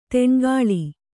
♪ teṇgāḷi